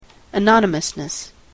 anonymousness.mp3